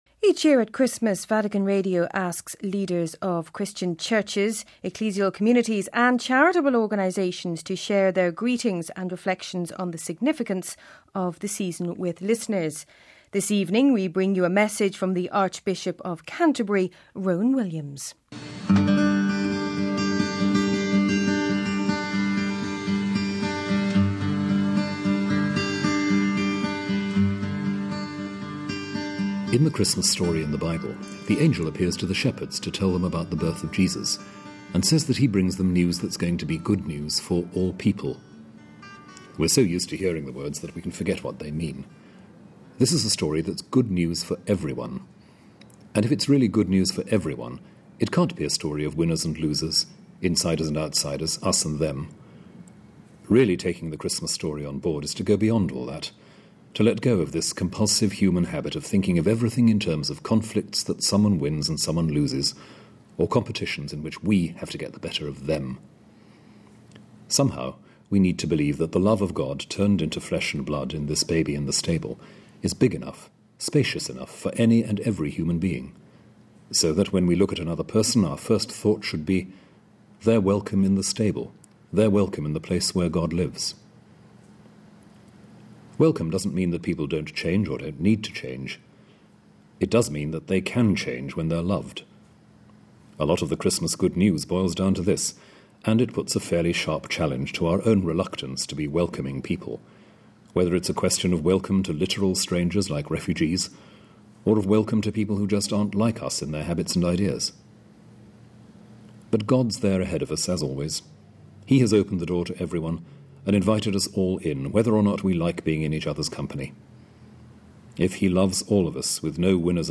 Christmas Greeting from the Archbishop of Canterbury